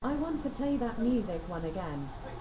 Libri-Trans-Spatialized_SLURP-Spatialized_dataset